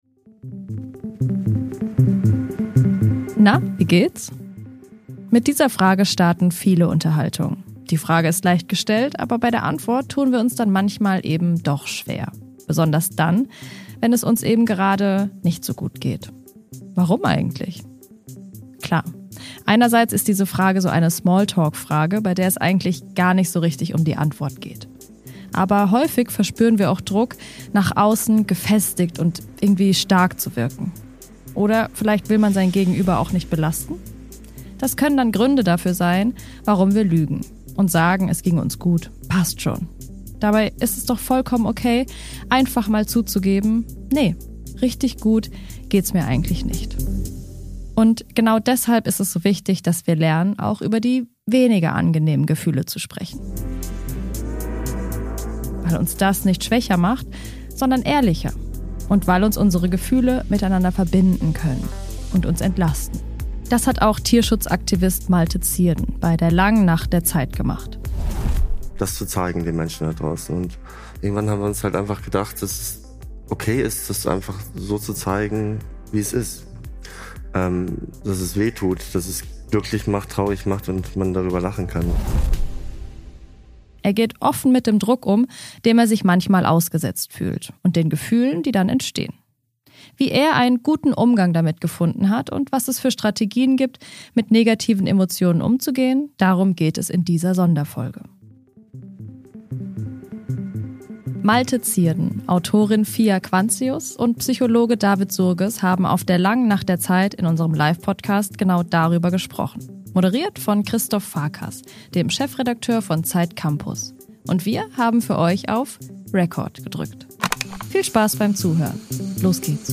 Darum geht es in dieser Sonderfolge, aufgenommen bei der „Langen Nacht der Zei…